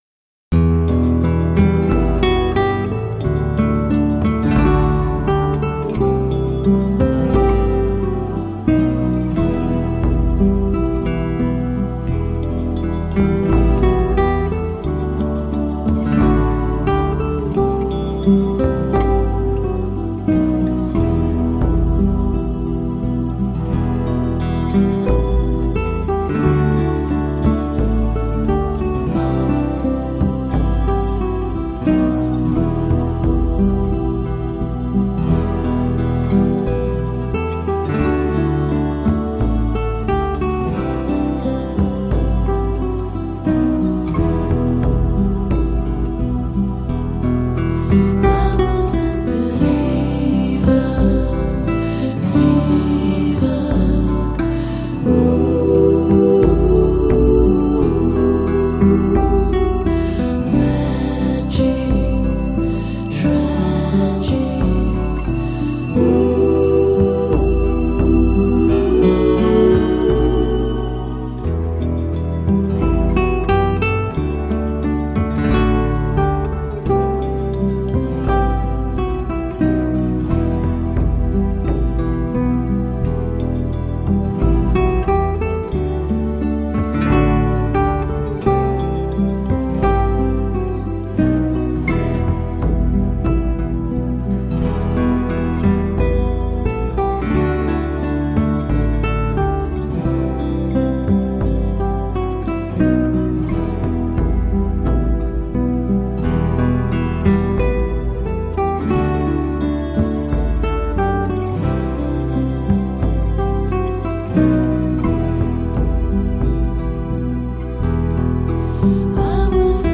音乐类型：纯音乐
西班牙/弗拉门戈优美的吉他和键盘音乐！
凡入圣，充满电影感的浪漫编曲风格配敲击音效，展现ＨｉＦｉ最高境界和享受。